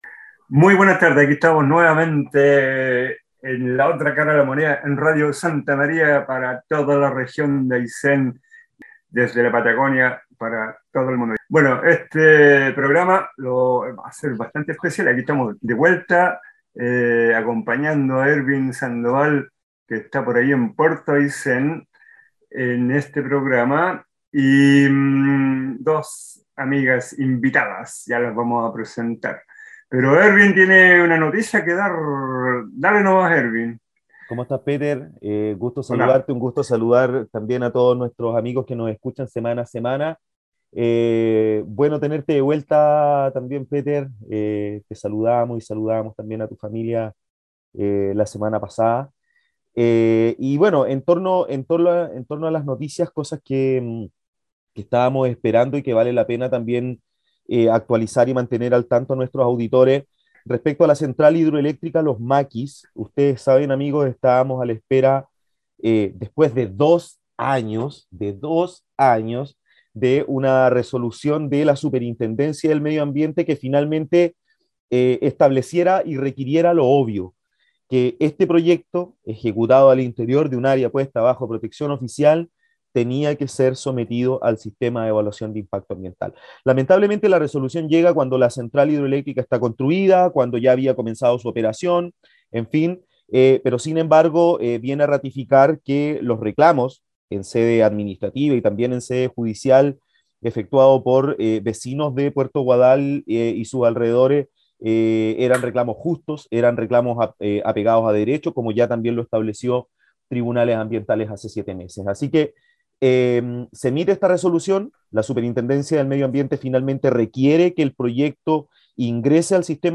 Conversamos con la biólogas marinas